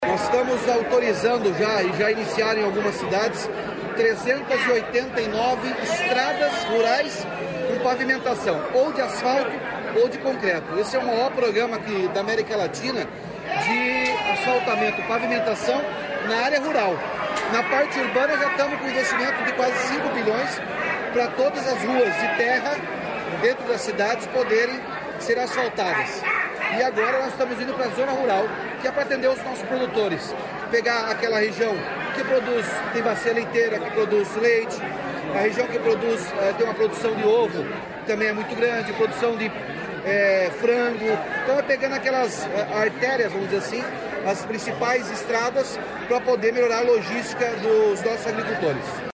Sonora do governador Ratinho Junior sobre a pavimentação em concreto de estradas rurais em Nova Esperança